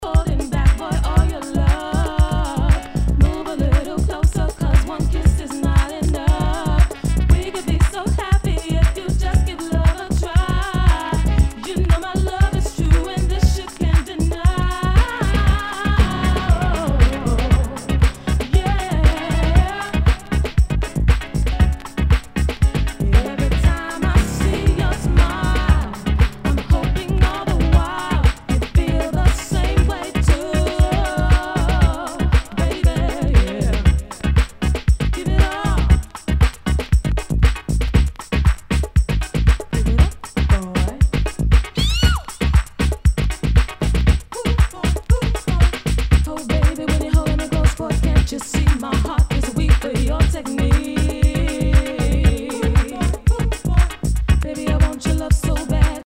HOUSE/TECHNO/ELECTRO
ナイス！ヴォーカル・ハウス・クラシック！
全体にチリノイズが入ります